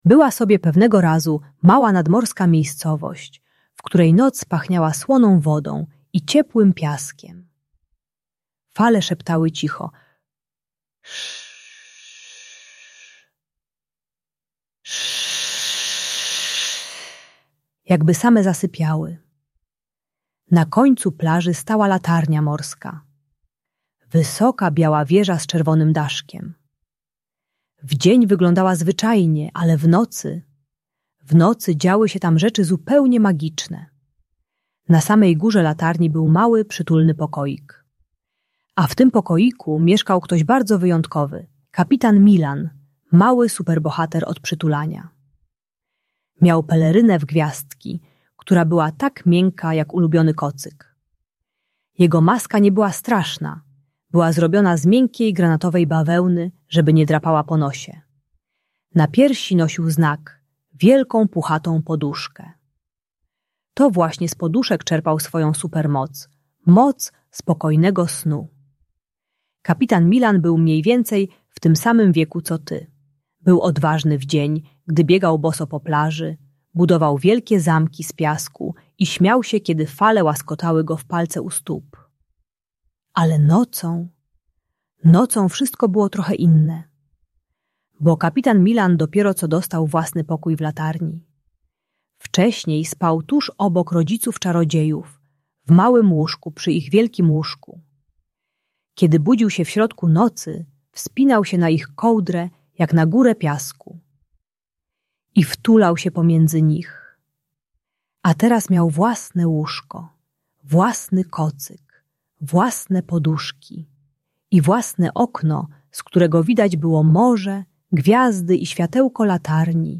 Ta bajka dla dziecka które boi się spać samodzielnie uczy techniki "bicia serca" - dziecko kładzie rękę na sercu i czuje, że rodzice są blisko. Audiobajka usypiająca z dźwiękami morza dla przedszkolaka przeprowadzającego się do własnego pokoju.